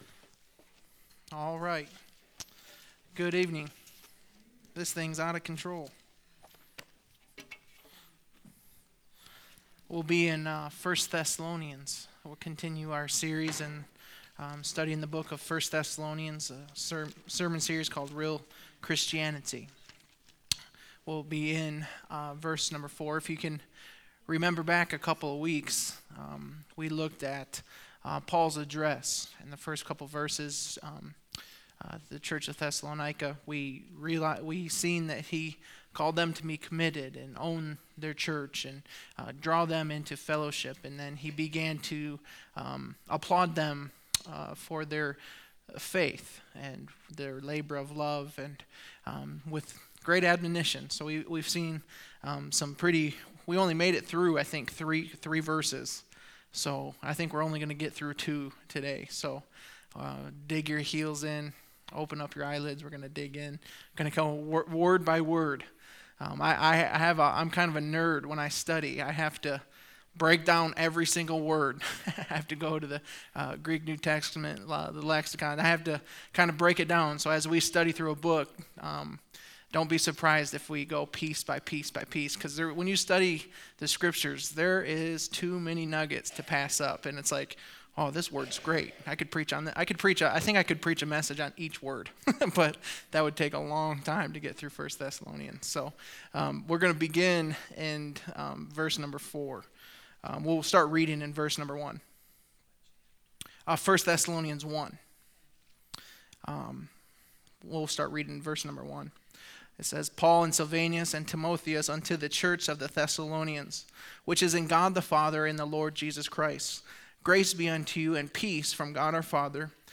Sermons | First Baptist Church of Fenton